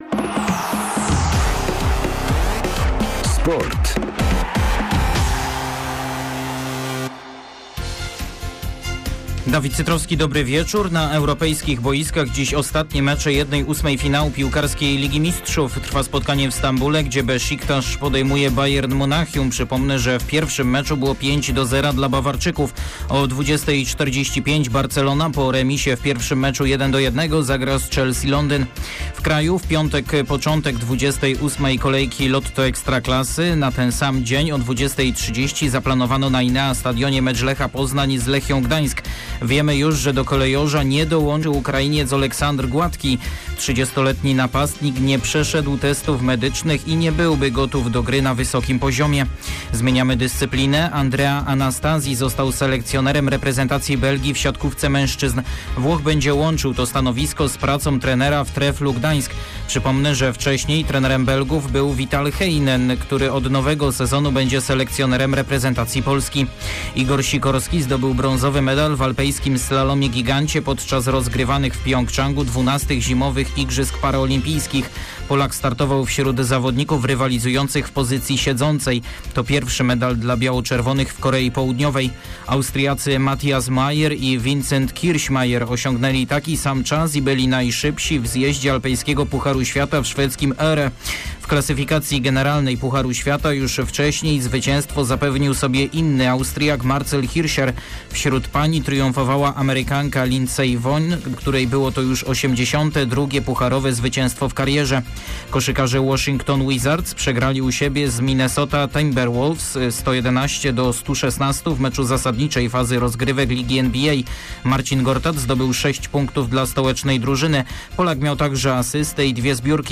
14.03 serwis sportowy godz. 19:05